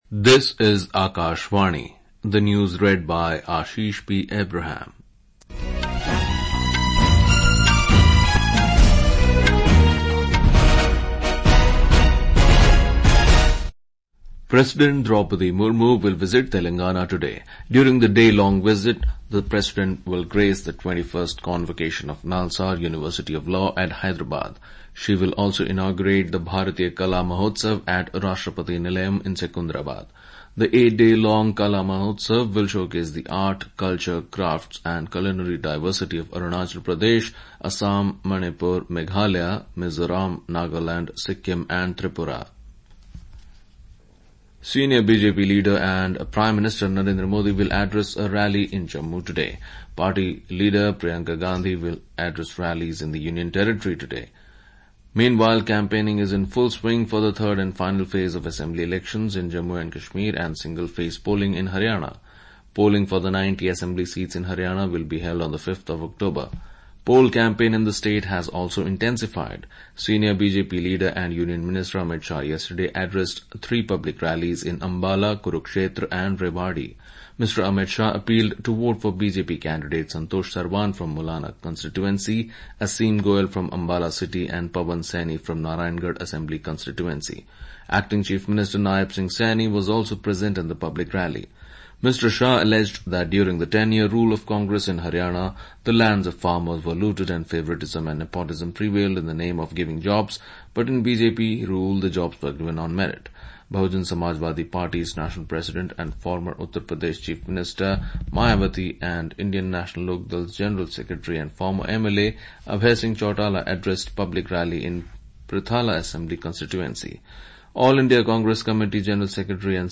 National Bulletins
Hourly News